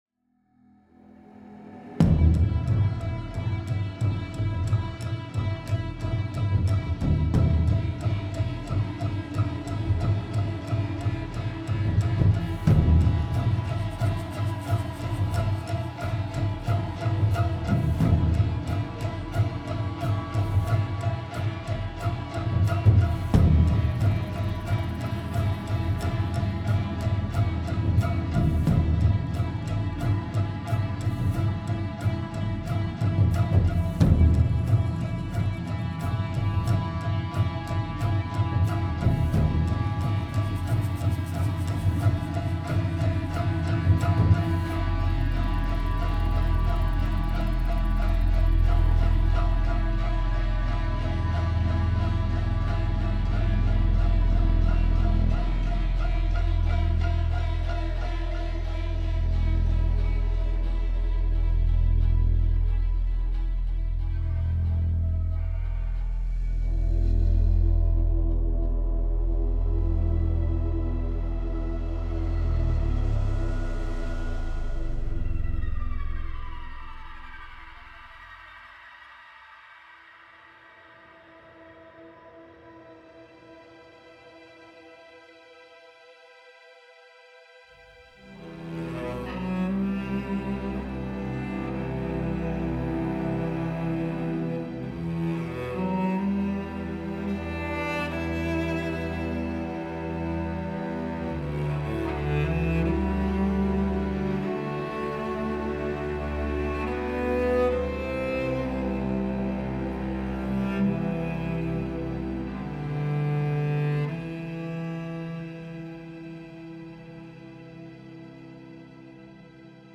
Жанр: Soundtrack